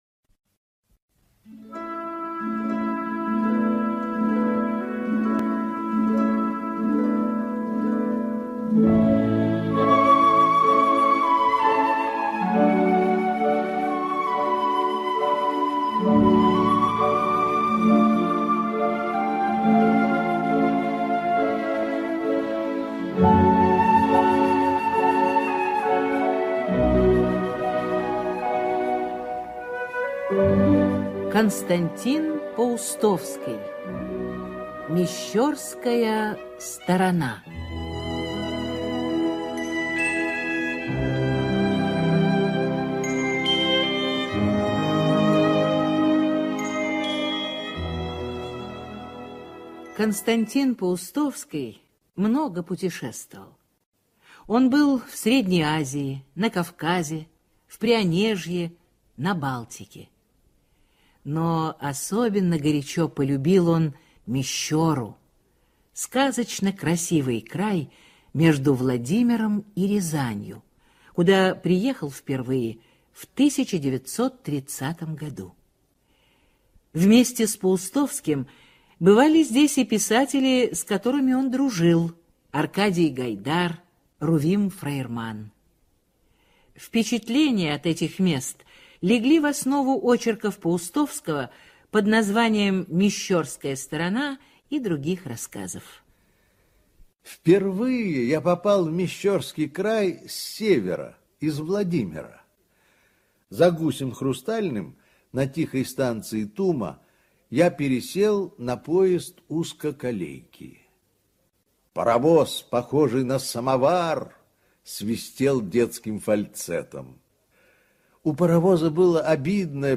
Мещёрская сторона – аудио рассказ Паустовского К.А. В этих рассказах Паустовский признается в большой любви к родной природе средней полосы.